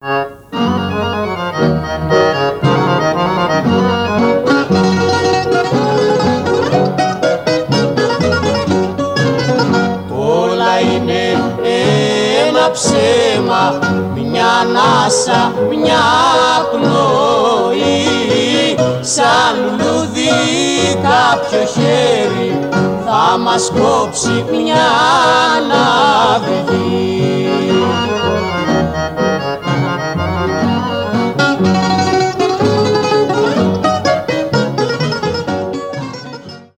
Рингтоны без слов
ретро
греческие , фолк